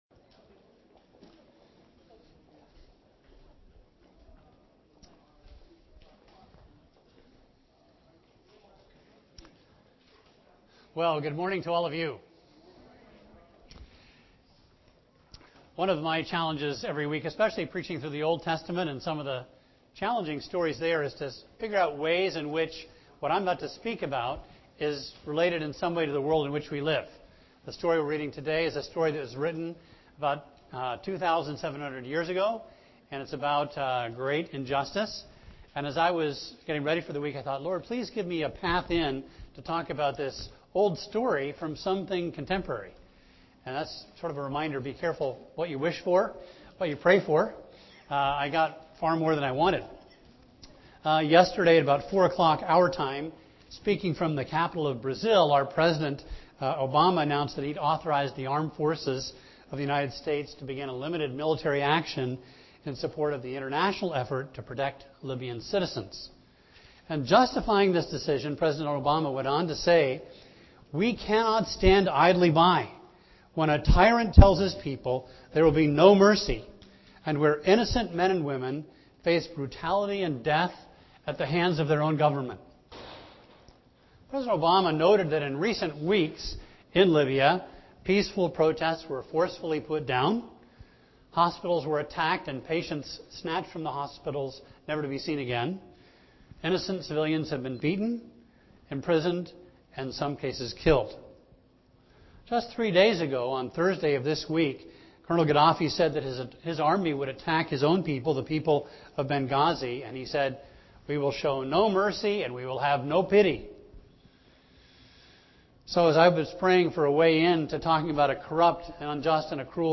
A message from the series "Elijah."